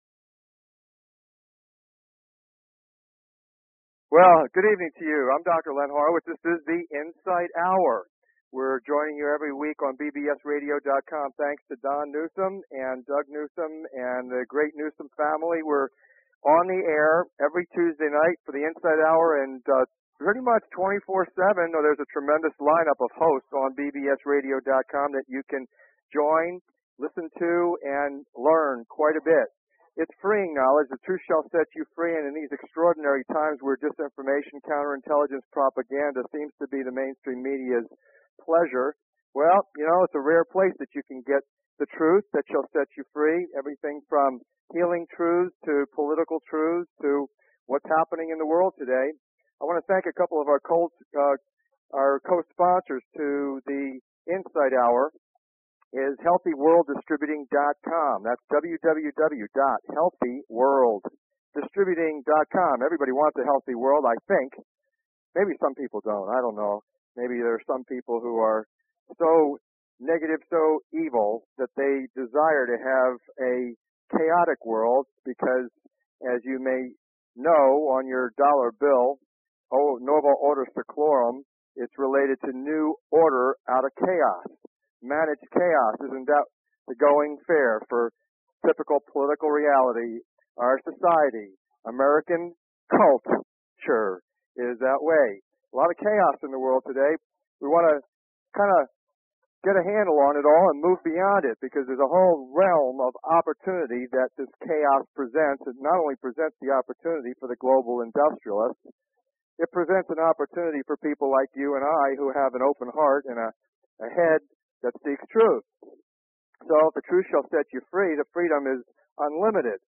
Talk Show Episode, Audio Podcast, The_Insight_Hour and Courtesy of BBS Radio on , show guests , about , categorized as